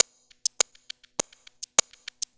Hi Hat 01.wav